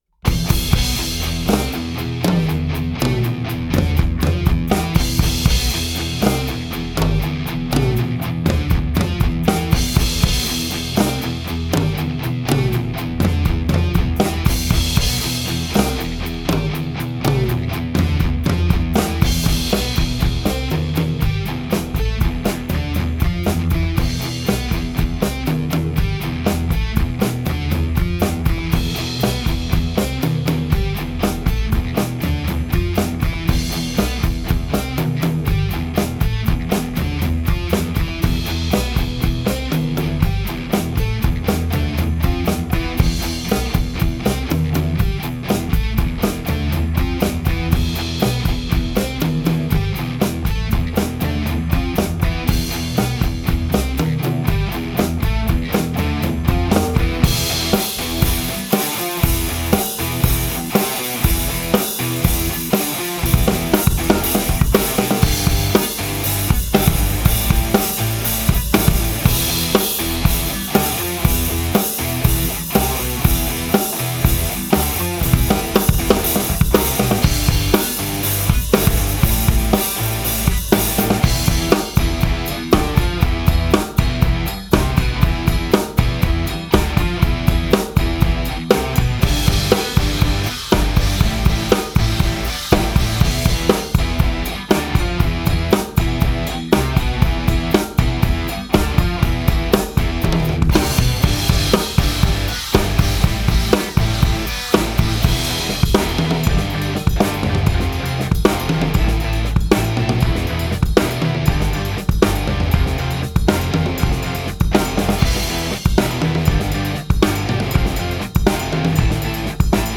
Playalong und Performance